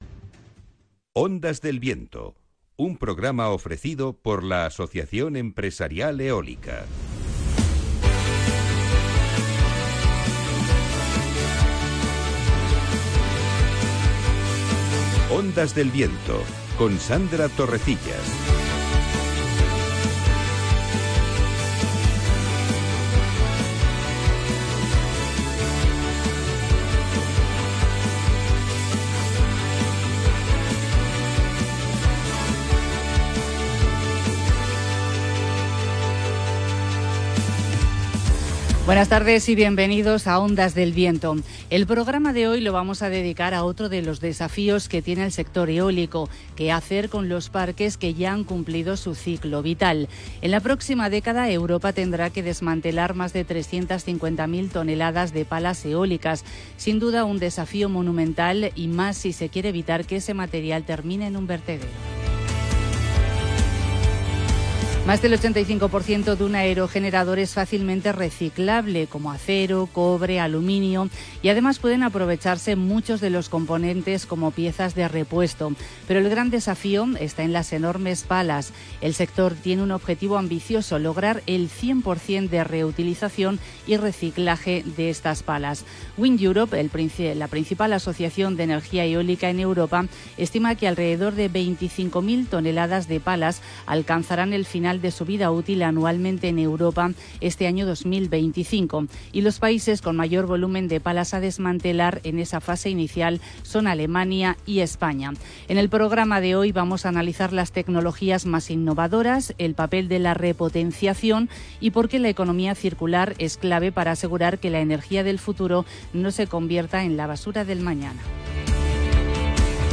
En este nuevo episodio de Ondas del Viento, el programa radiofónico del sector eólico en la emisora Capital Radio, hemos analizado qué hacer con los parques eólicos que ya han cumplido su ciclo vital con el papel de la repotenciación y la economía circular para asegurar un correcto reciclaje.